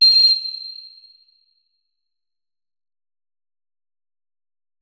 flute
notes-84.ogg